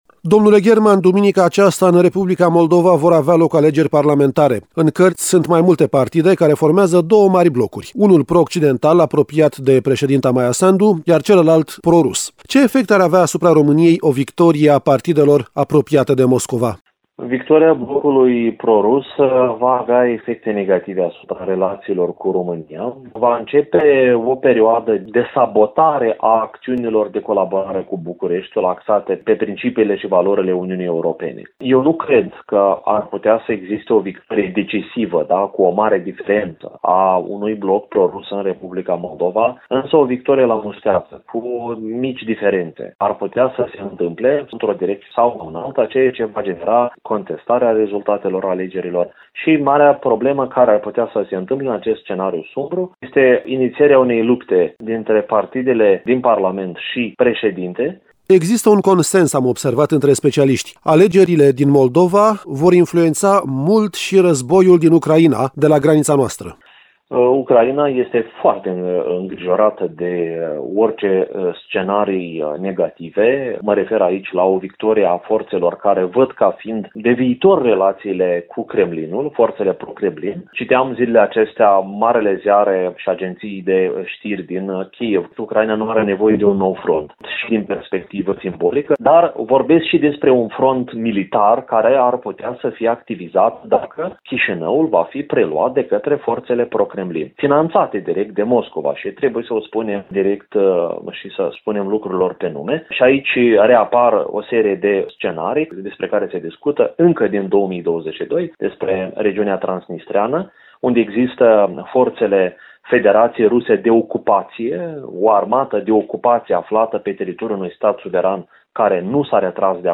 AUDIO | În Republica Moldova vor avea loc, duminica aceasta, alegeri parlamentare. Interviu cu lect. univ.